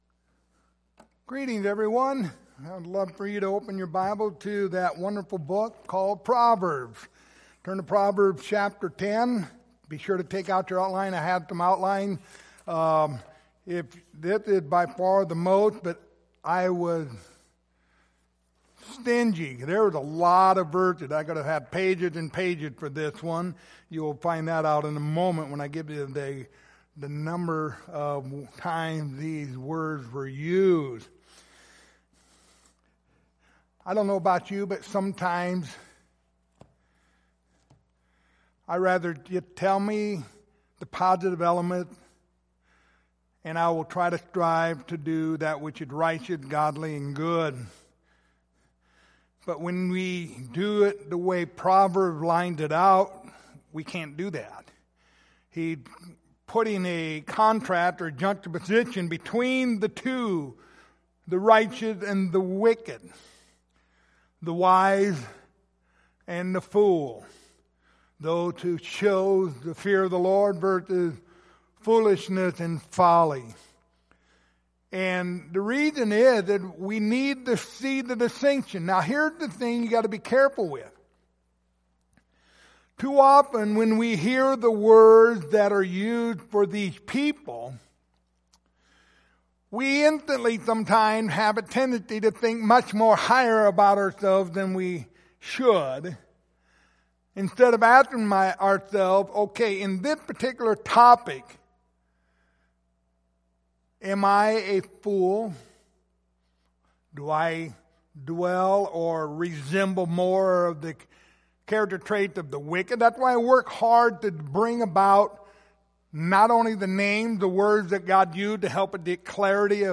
The Book of Proverbs Passage: Proverbs 10:27-32 Service Type: Sunday Evening Topics